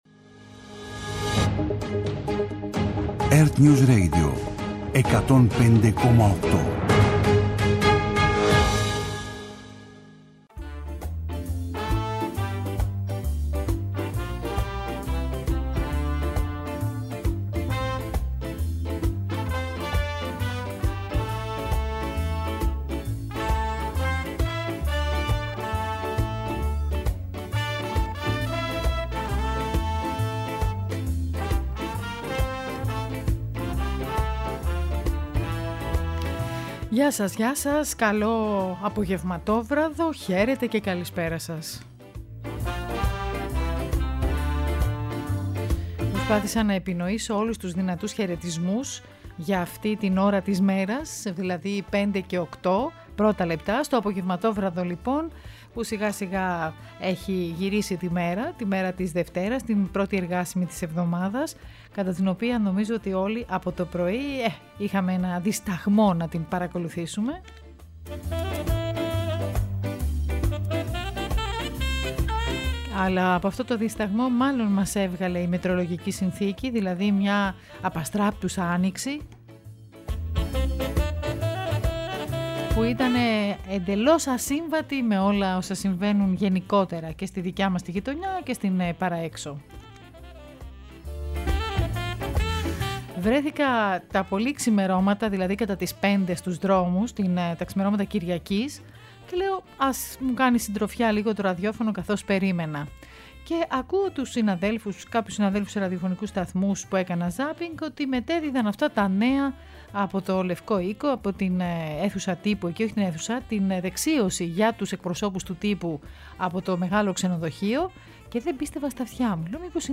-Απ ευθείας μετάδοση της έκτακτης συνέντευξης Τύπου του Νίκου Ανδρουλάκη, Προέδρου ΠΑΣΟΚ-ΚΙΝΑΛ για την υπόθεση των υποκλοπών